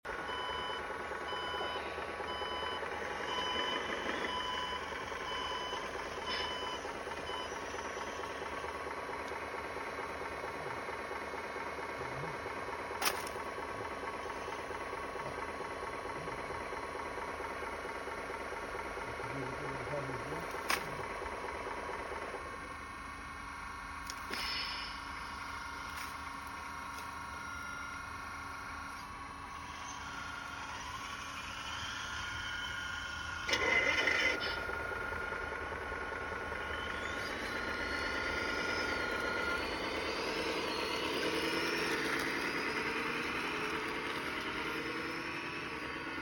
CAT 6015B RC EXCAVATOR LOADING Sound Effects Free Download